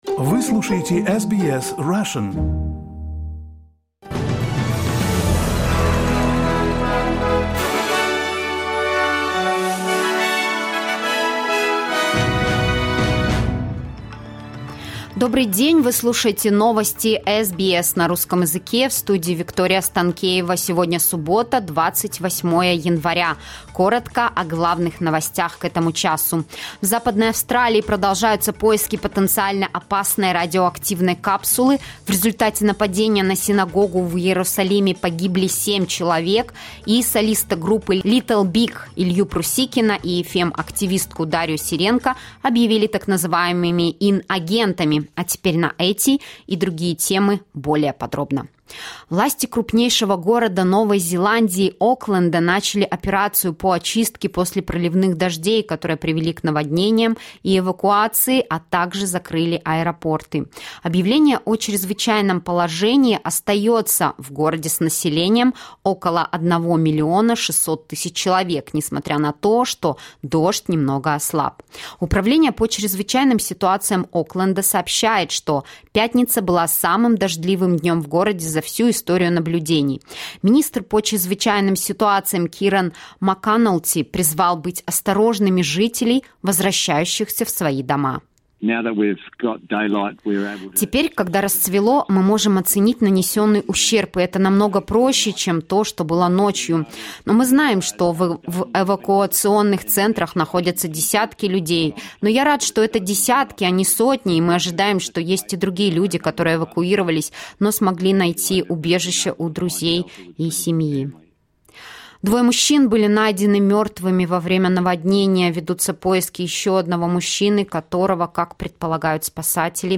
SBS news in Russian — 28.01.2023